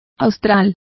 Complete with pronunciation of the translation of south.